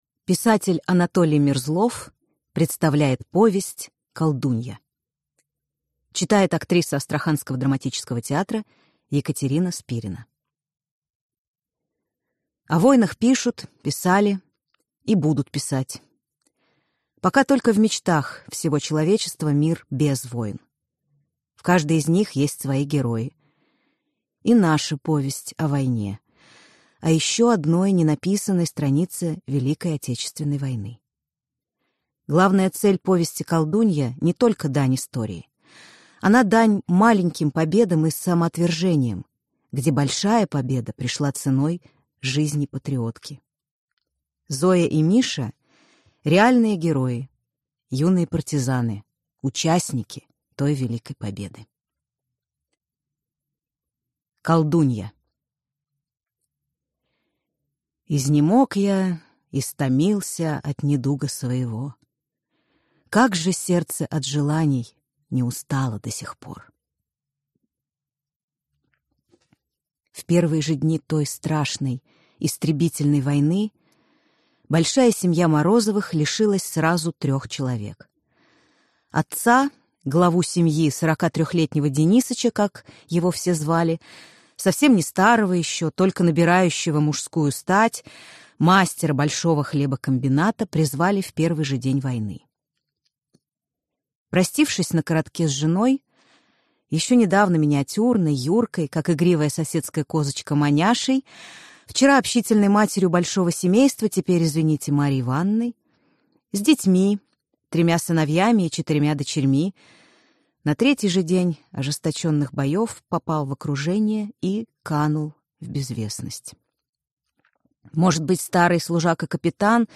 Аудиокнига Колдунья | Библиотека аудиокниг